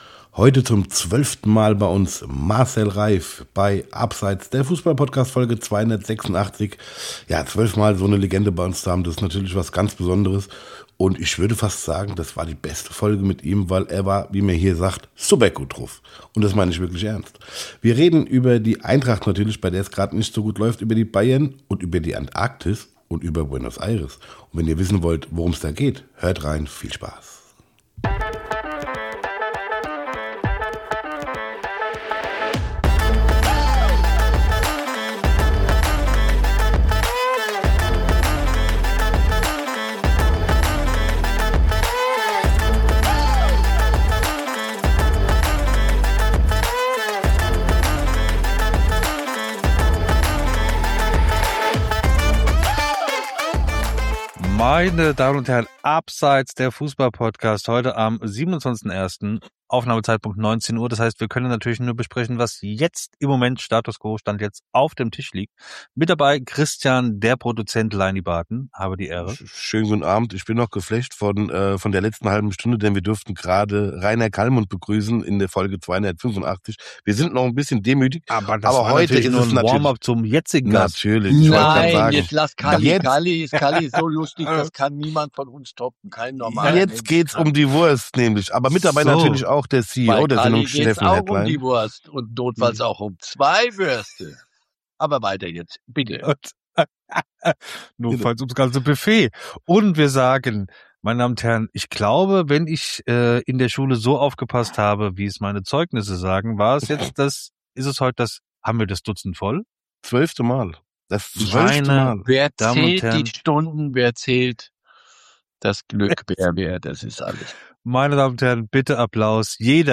Zum zwölften (!) Mal ist heute Marcel Reif unser Gast. Wir diskutieren hitzig über die Eintracht, natürlich geht es auch um die Bayern, und Herr Reif erklärt uns, warum Pinguine die besseren Menschen sind.